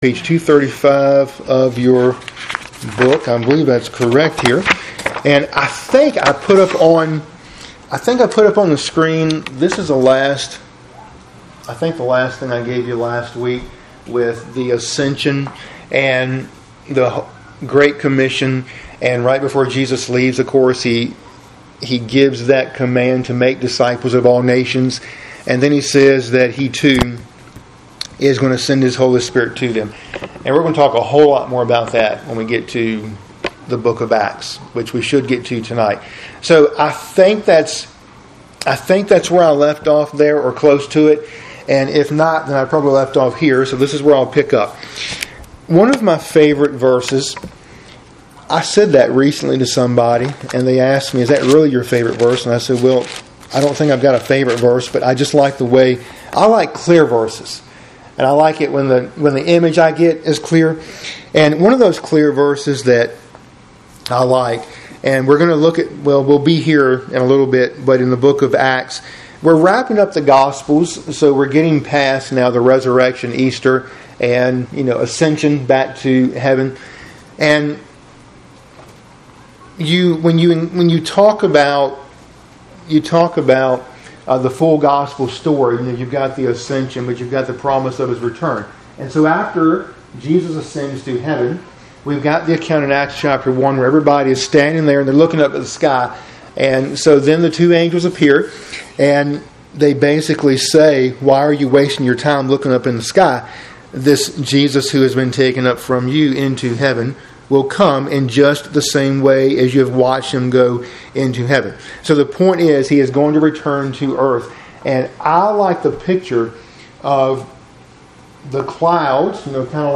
Midweek Bible Study – Lessons 48 & 49